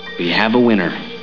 Here you will find various sounds taken from Buffy, the Vampire Slayer.